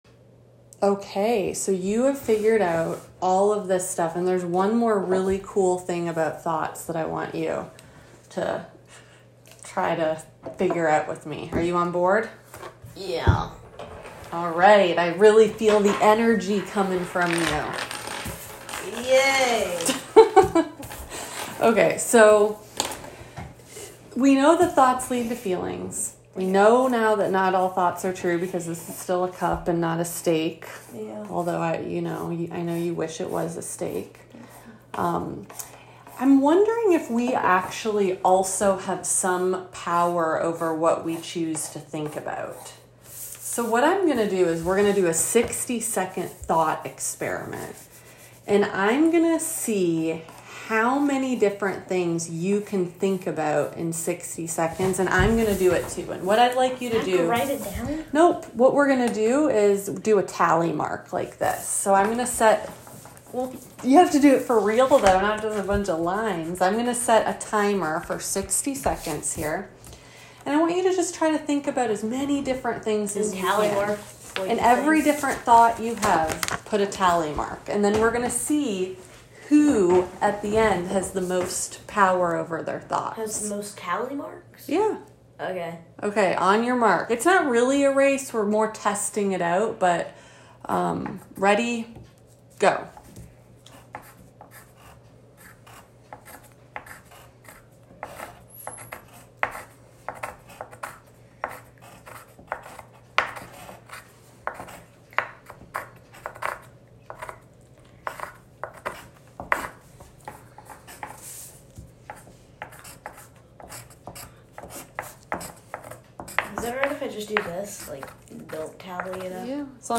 Below you will find audio recordings of sample techniques for various Exposure-Based CBT sessions with kids.
Child Therapist Demo: We Have Some Control Over Thoughts